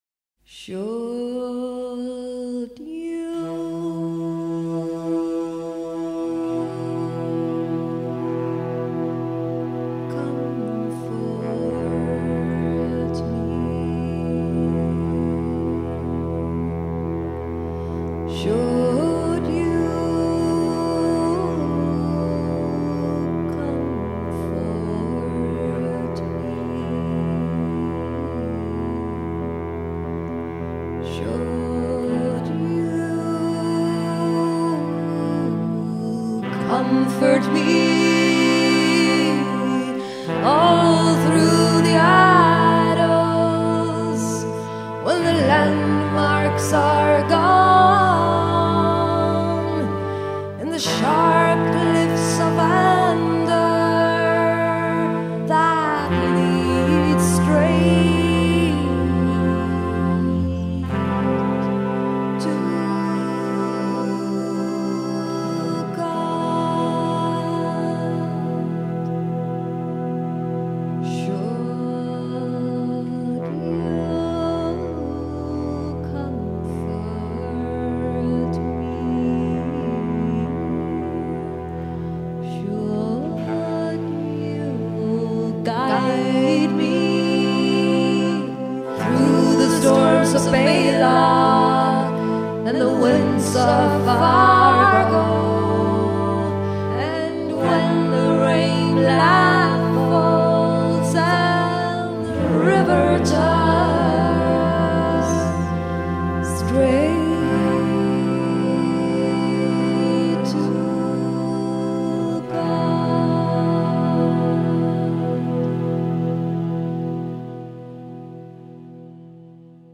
VOCALS, SAXOPHONES